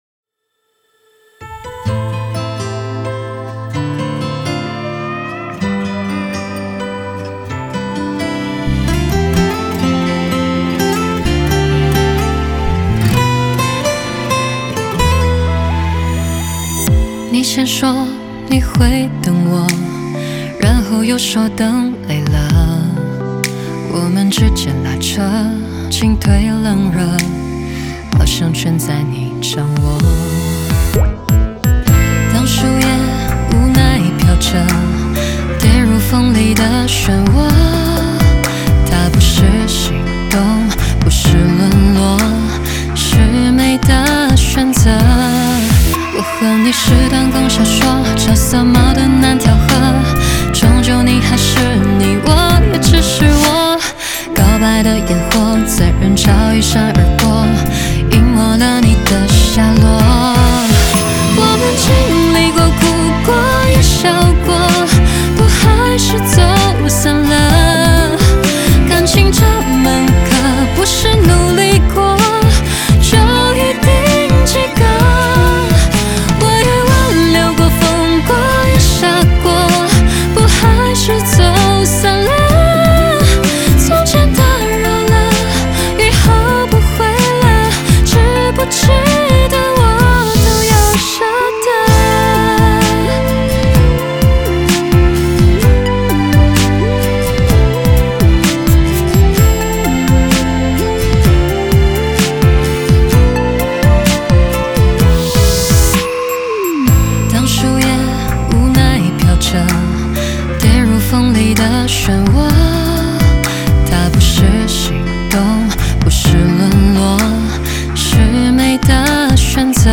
Ps：在线试听为压缩音质节选，体验无损音质请下载完整版
吉他
和声